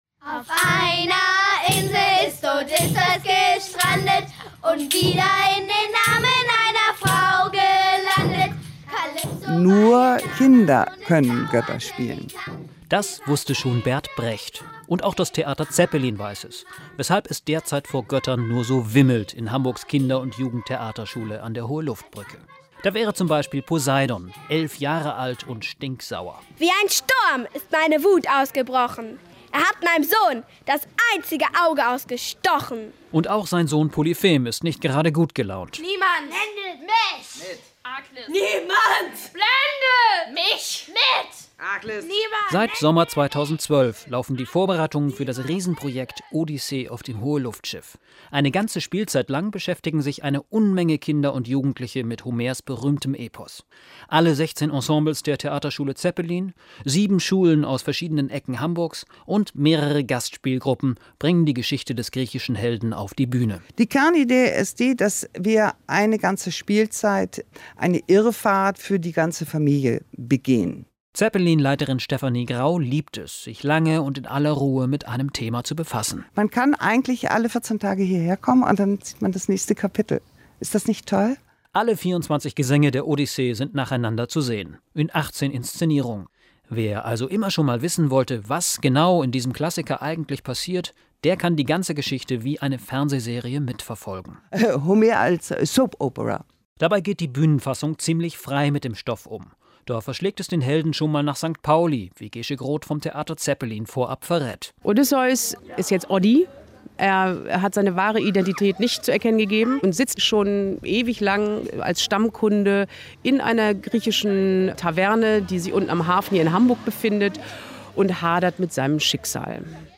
Der Beitrag wurde am 5. September auf NDR 90,9 im Abendjournal gesendet.